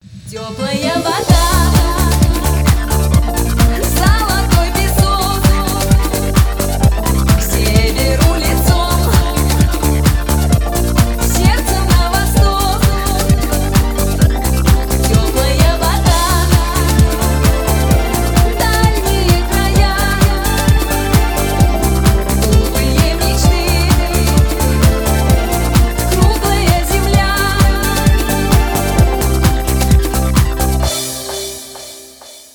поп
танцевальные